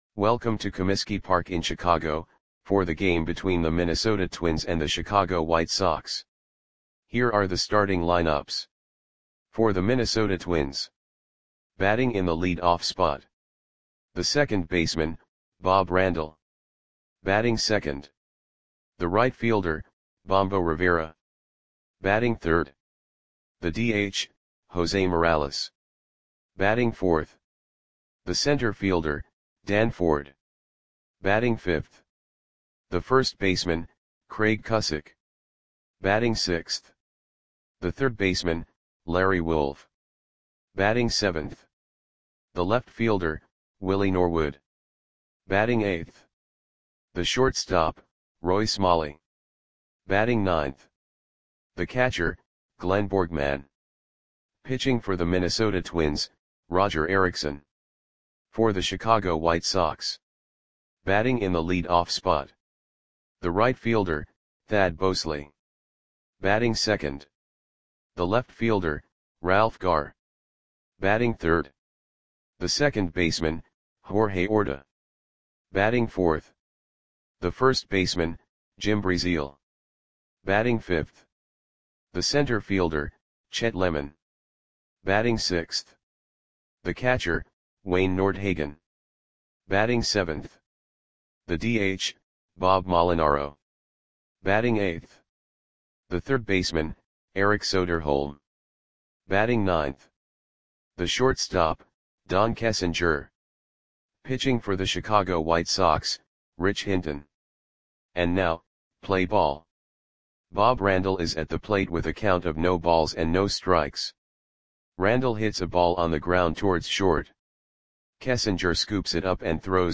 Audio Play-by-Play for Chicago White Sox on June 25, 1978
Click the button below to listen to the audio play-by-play.